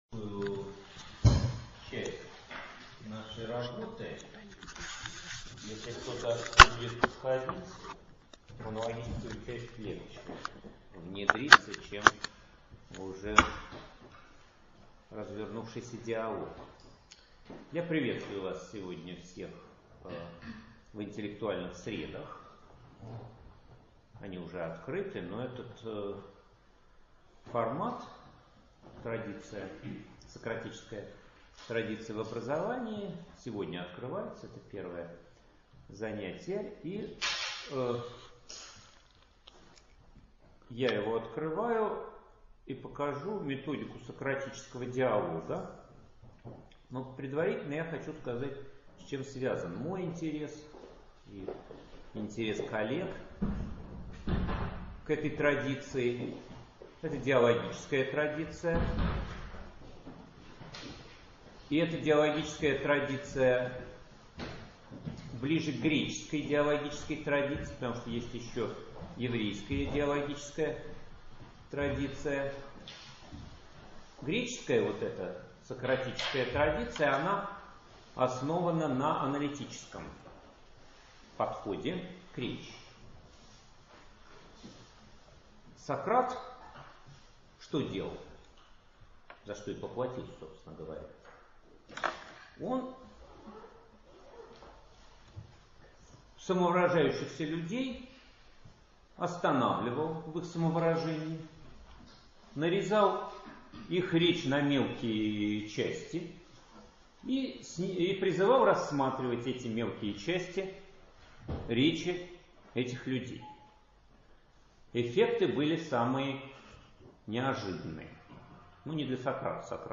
Аудиокнига Сократическая традиция в образовании | Библиотека аудиокниг